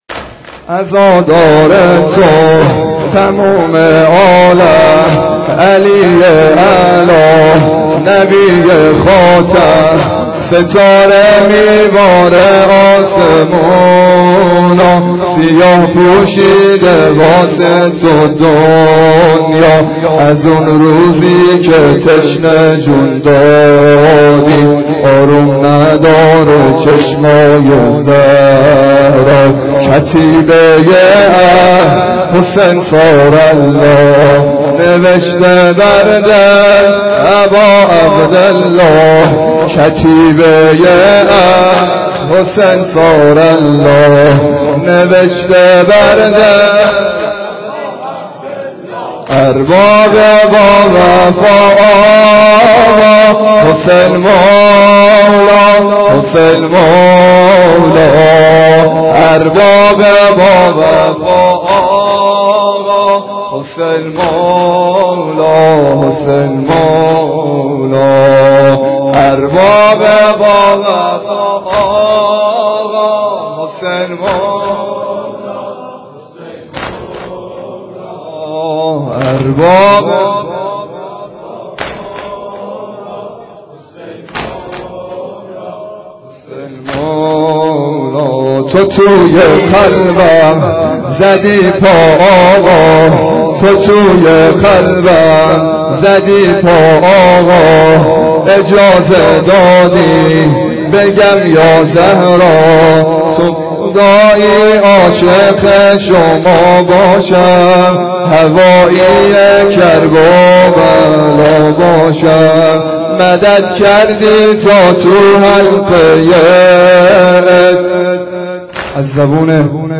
واحد تند